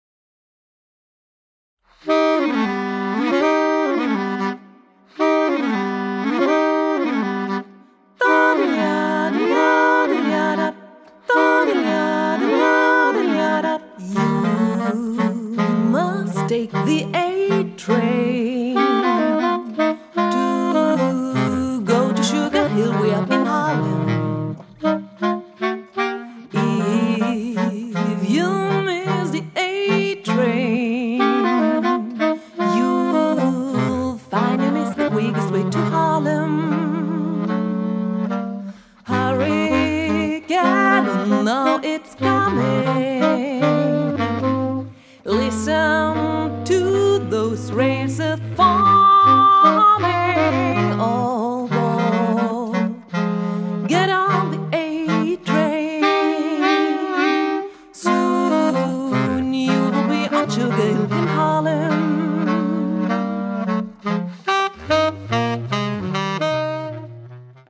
Trio voc/sax/bc